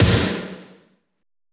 THRASH SN.wav